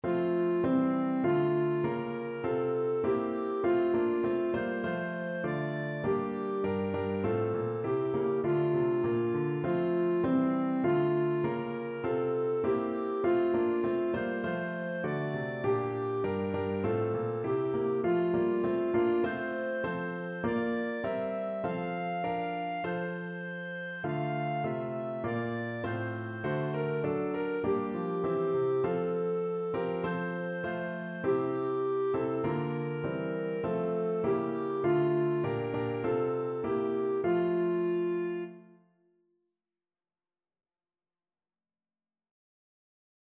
Clarinet
F major (Sounding Pitch) G major (Clarinet in Bb) (View more F major Music for Clarinet )
4/4 (View more 4/4 Music)
Moderato
Traditional (View more Traditional Clarinet Music)